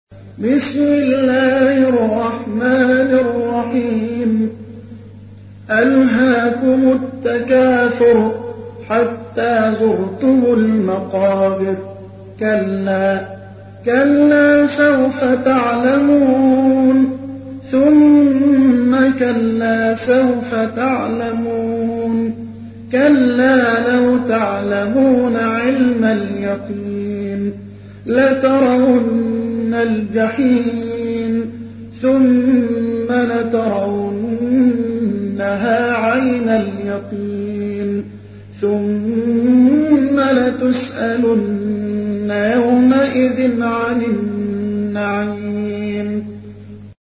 تحميل : 102. سورة التكاثر / القارئ محمد حسان / القرآن الكريم / موقع يا حسين